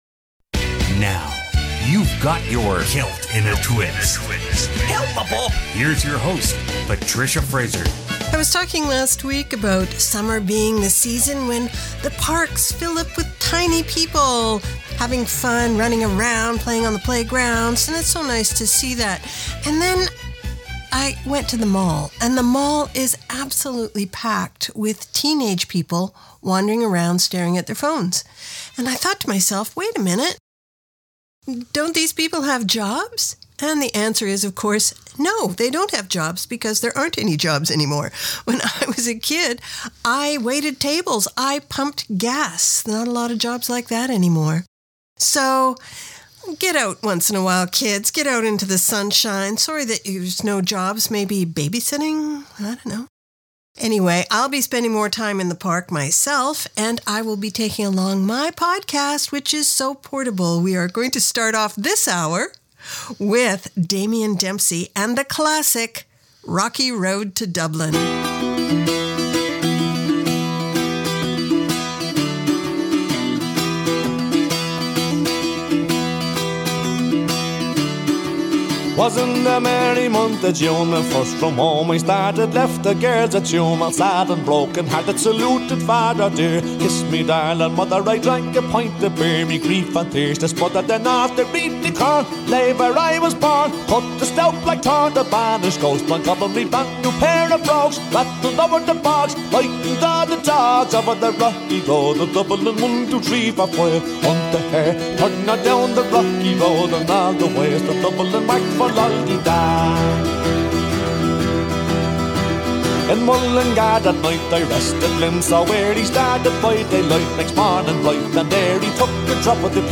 Canada's Contemporary Celtic Hour